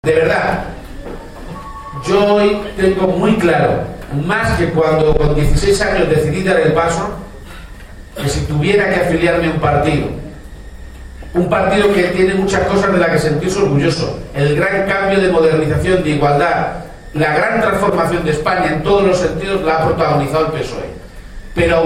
Durante un encuentro con militantes y simpatizantes del PSOE, celebrado en el Mesón Casa Antonio de Tobarra (Albacete)
Cortes de audio de la rueda de prensa